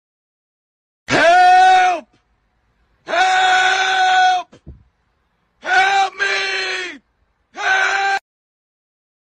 Help-Me__-Sound-Effect-128kbps.mp3